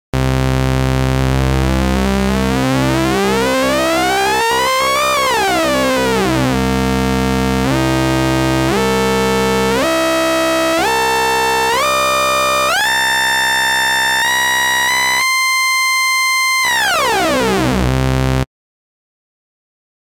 OscSync
Category: Sound FX   Right: Personal
Tags: Sound Effects Orca Demos FXpansion Orca FXpansion Soft Synth